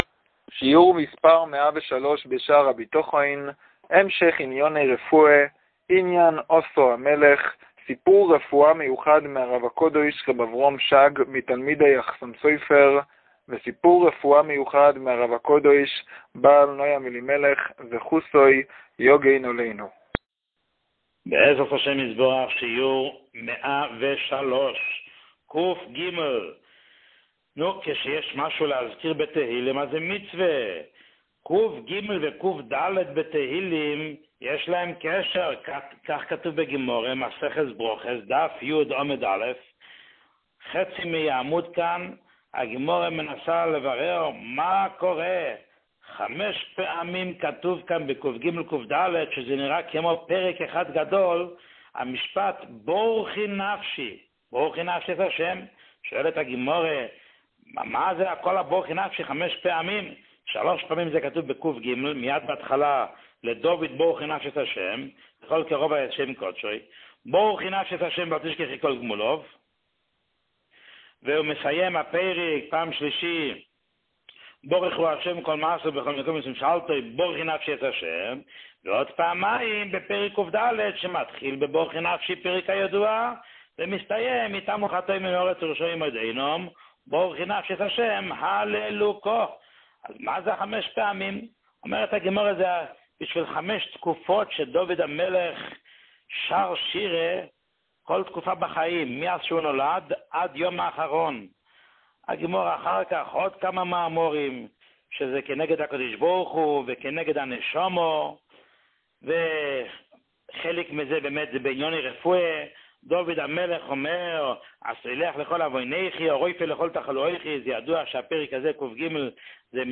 שיעור 103